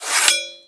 knife_deploy1.wav